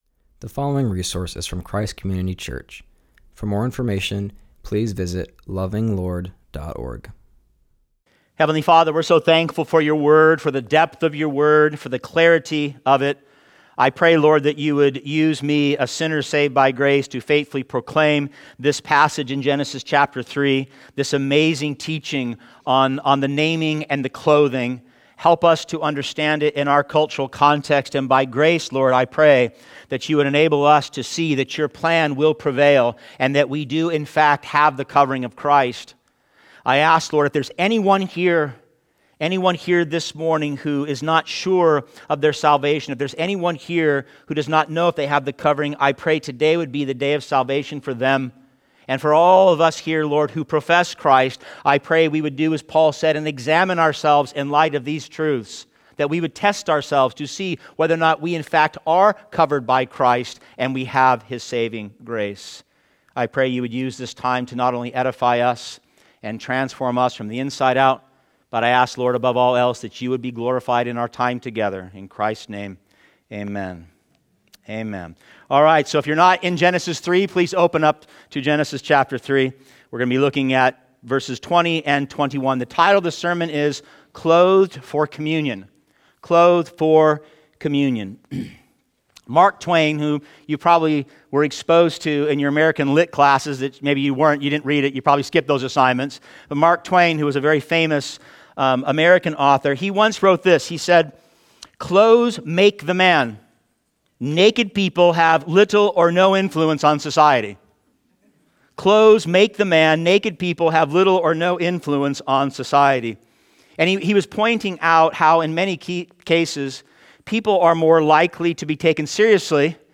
preaches from Genesis 3:20-21.